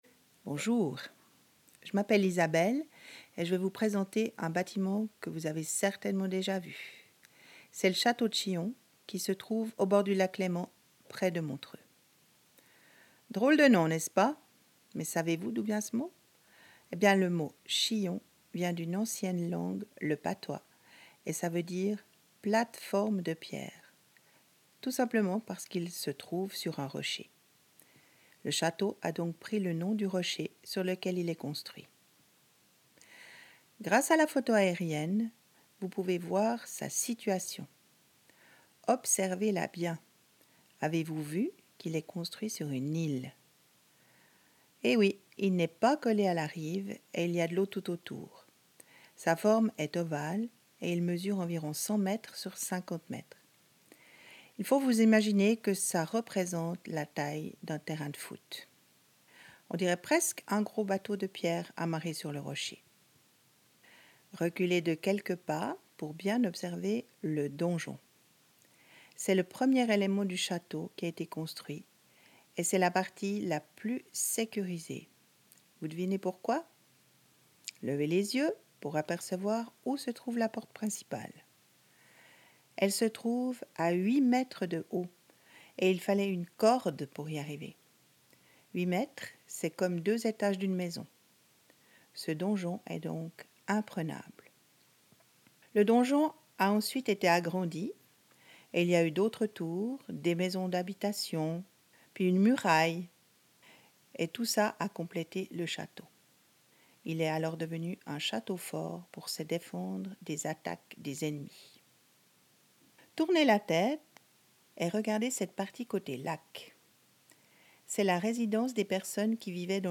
Catalogue : Audioguide